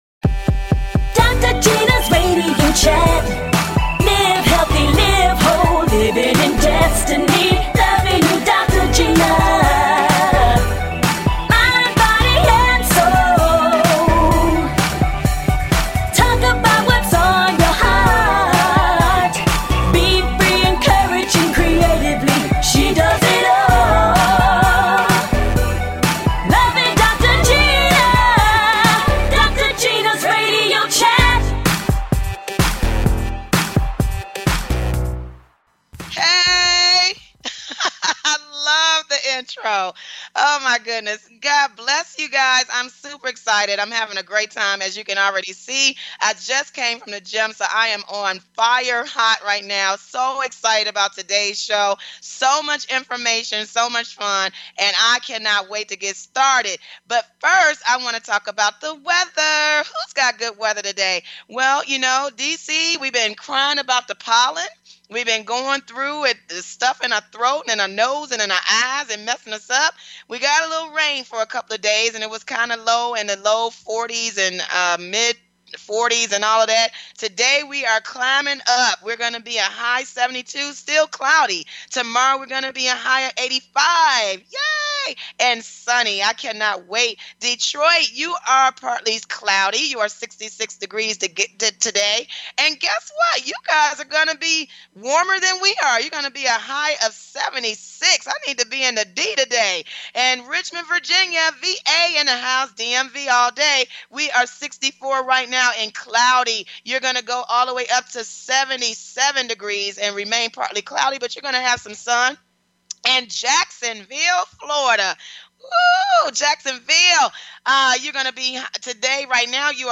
Talk Show Episode
Guests, Eddie Levert and Comedian